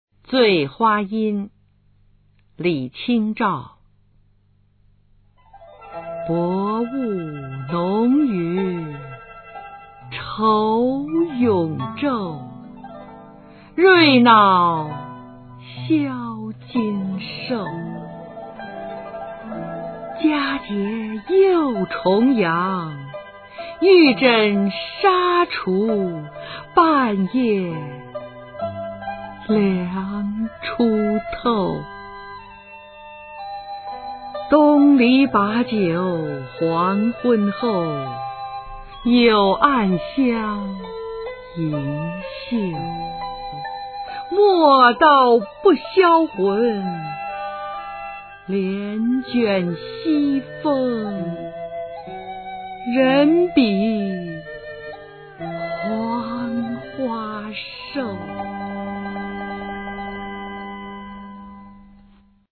李清照《醉花阴》原文和译文（含鉴赏、朗读）